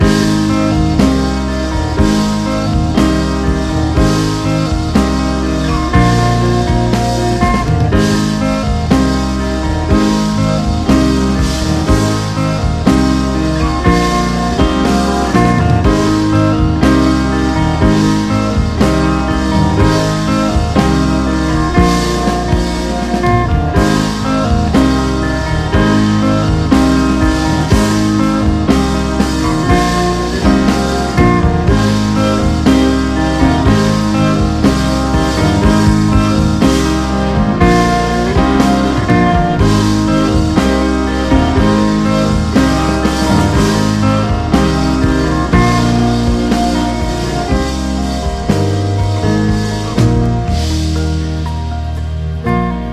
JAPANESE SOFT ROCK
青春ビート歌謡/和モノ・ソフトロック・クラシック！